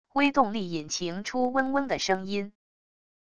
微动力引擎出嗡嗡的声音wav音频